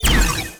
Sci-Fi Effects
weapon_energy_beam_003_open.wav